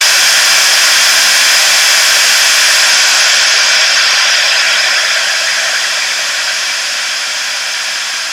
We’re talking white noise – a sound spectrum that puts out energy evenly across the audible spectrum.
This is what artificially-generated white noise sounds like:
The white noise is pretty even from about 1300Hz up to 6kHz.
white-noise-wiki.mp3